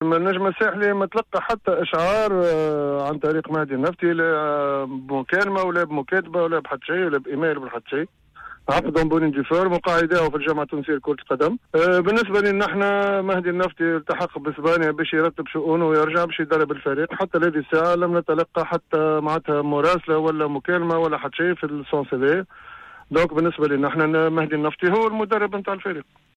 في مداخلته معنا اليوم في برنامج "cartes sur table "